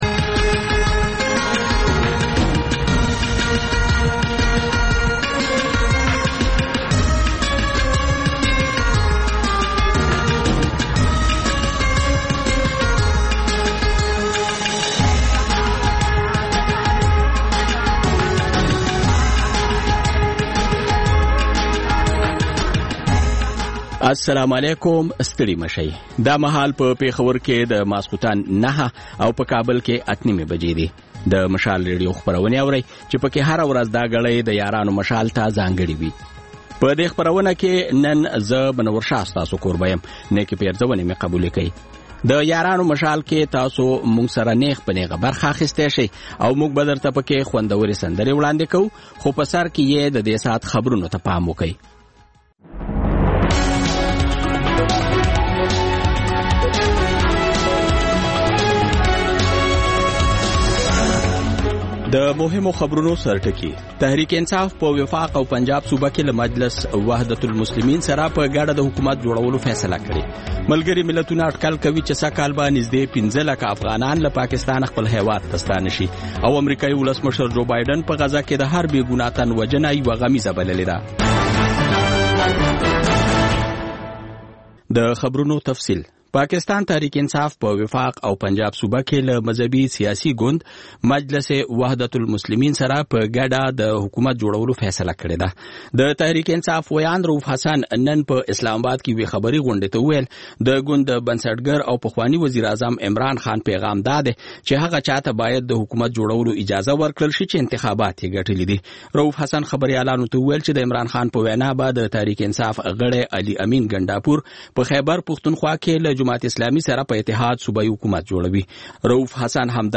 د یارانو مشال په ژوندۍ خپرونه کې له اورېدونکو سره بنډار لرو او سندرې خپروو.